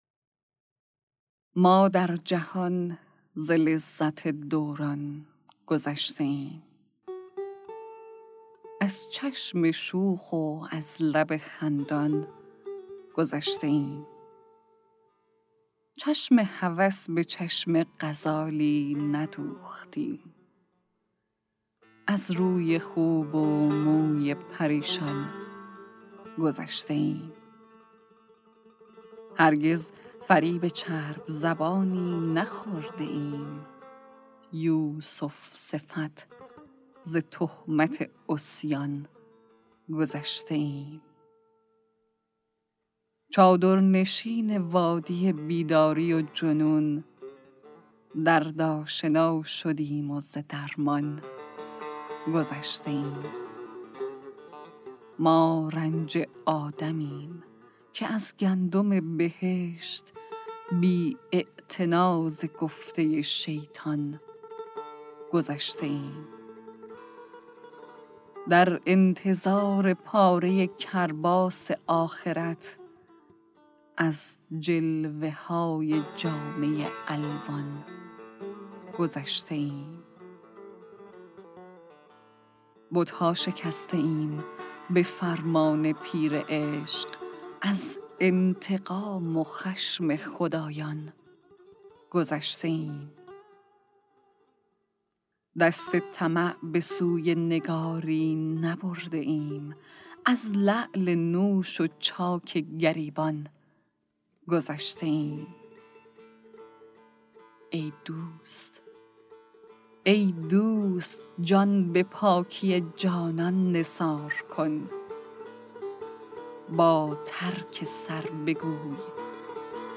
«شمال حماسه» کتاب صوتی اشعار شاعران شهید استان گیلان